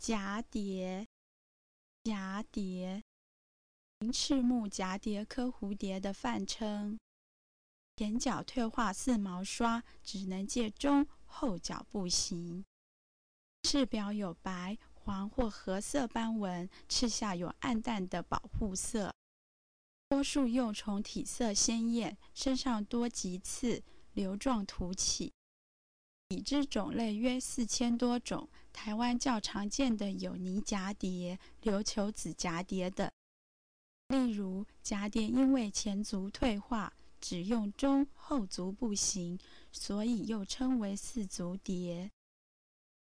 Advanced Information 【蛺】 虫 -7-13 Word 蛺蝶 Pronunciation ㄐㄧㄚ ˊ ㄉㄧㄝ ˊ ▶ Definition 鱗翅目蛺蝶科蝴蝶的泛稱。